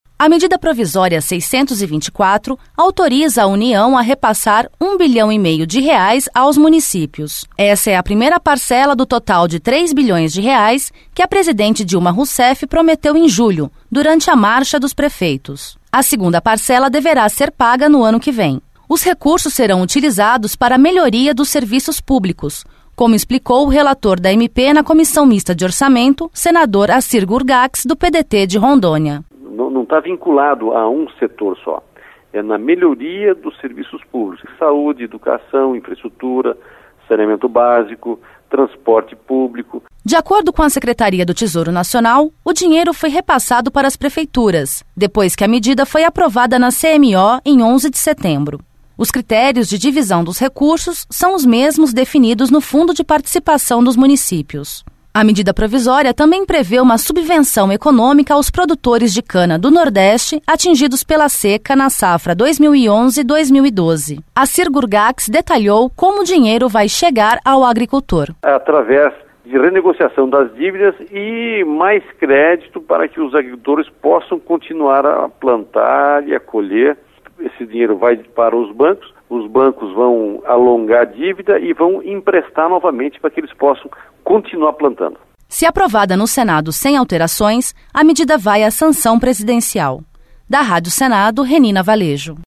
Senador Acir Gurgacz